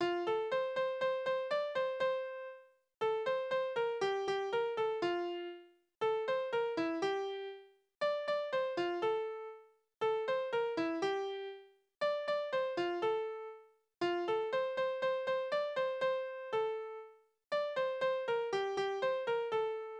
Liebeslieder:
Tonart: F-Dur
Taktart: 4/4
Tonumfang: kleine Septime
Besetzung: vokal